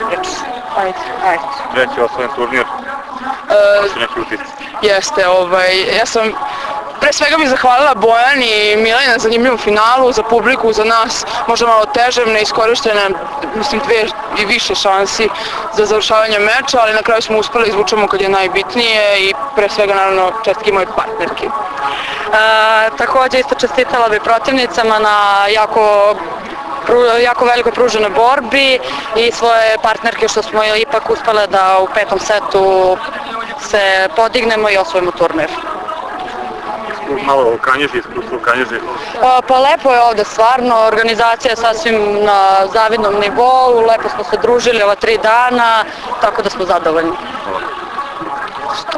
IZJAVE